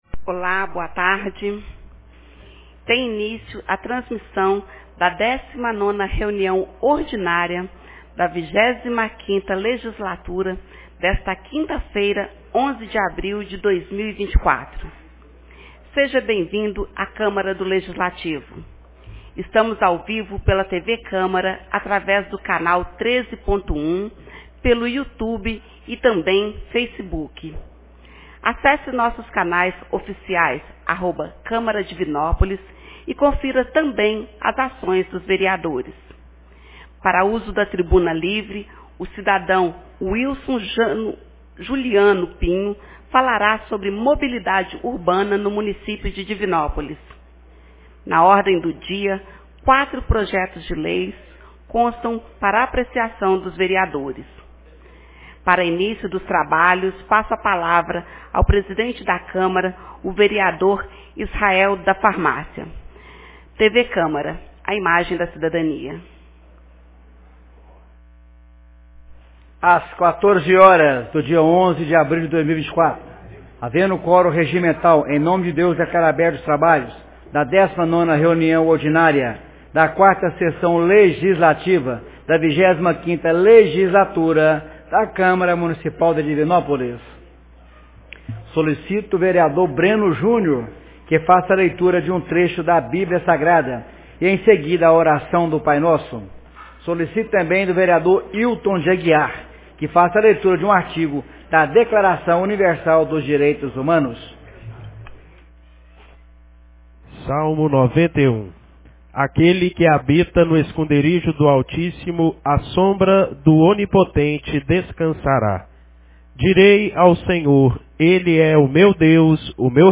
19ª Reunião Ordinária 11 de abril de 2024